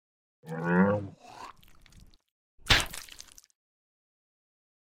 Звук верблюда плюнул и харкнул